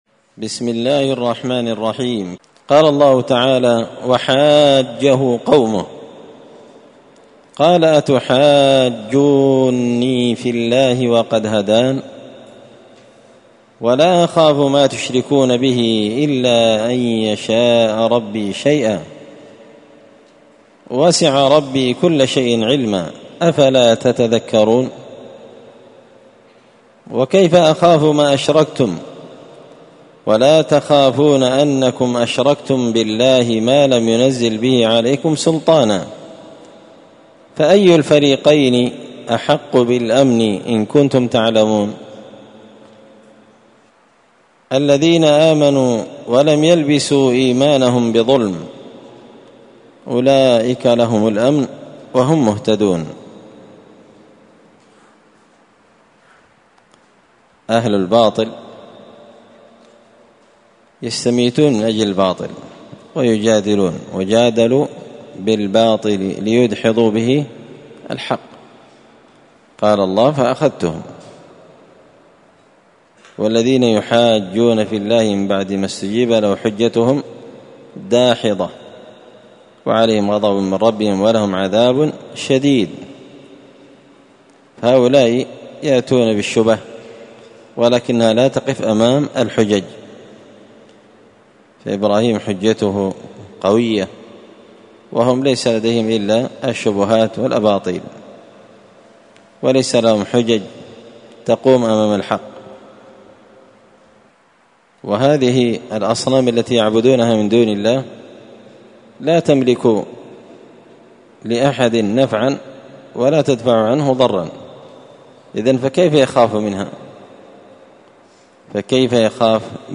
مختصر تفسير الإمام البغوي رحمه الله الدرس 325